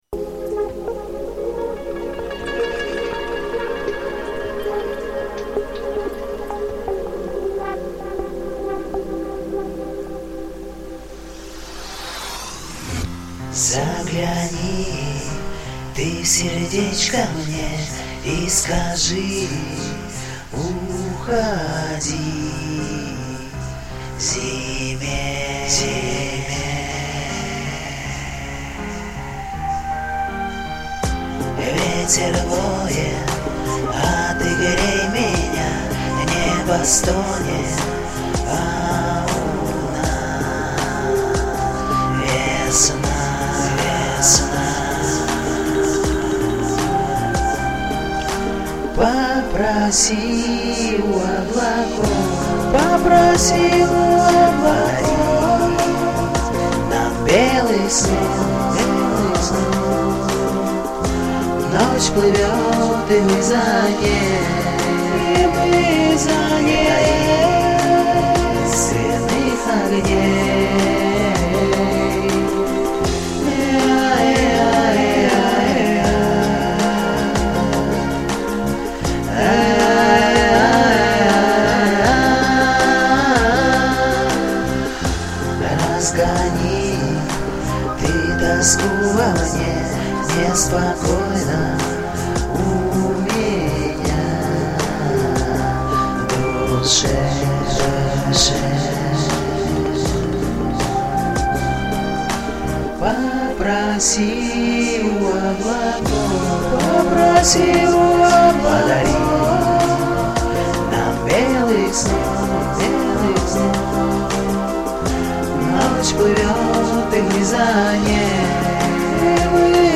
Потом начались многоголосия, деление партий - и я заценила.